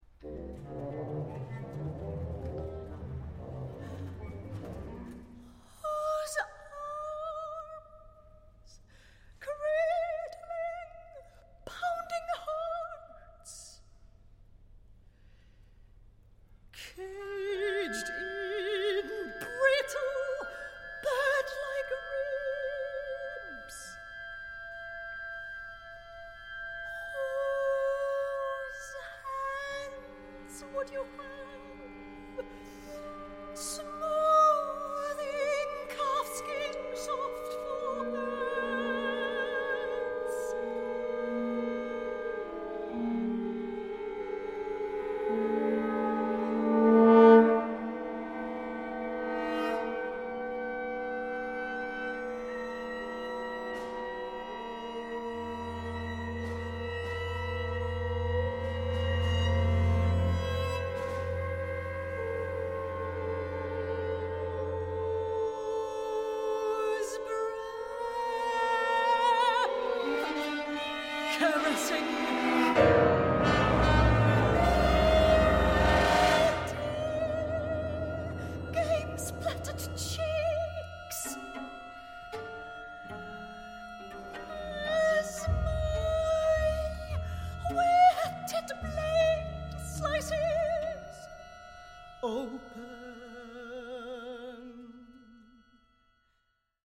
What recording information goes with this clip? Westdeutscher Rundfunk, Cologne, Germany